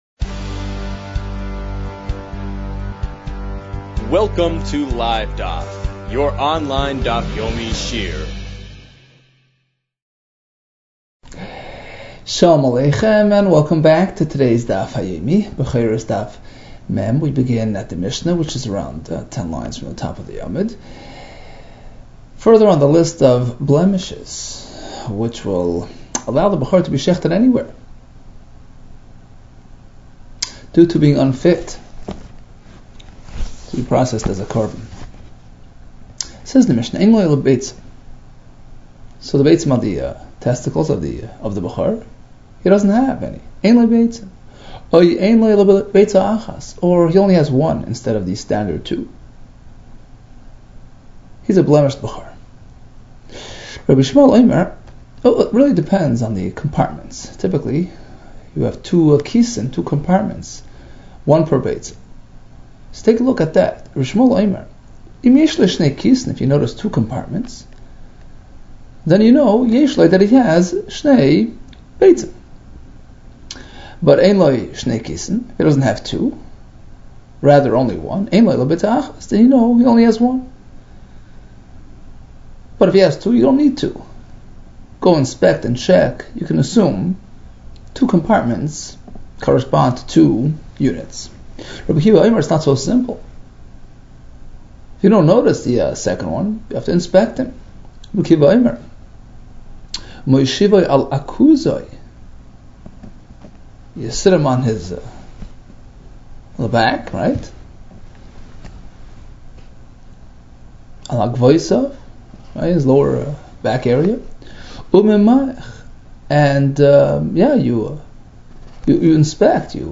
Bechoros 39 - בכורות לט | Daf Yomi Online Shiur | Livedaf